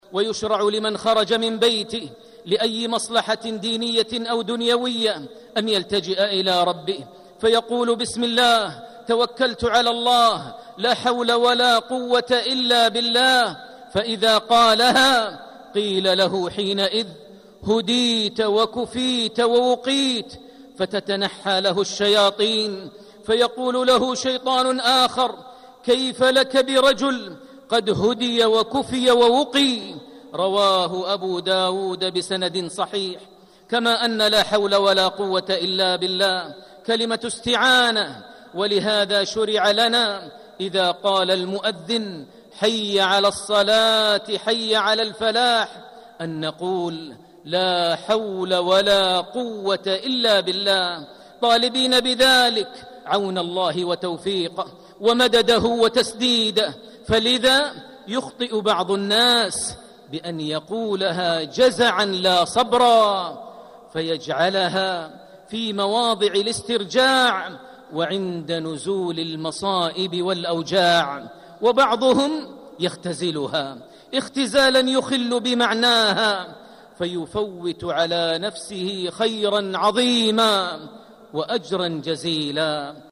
مقطع من خطبة الجمعة: فضل ومعنى لا حول ولا قوة إلا بالله.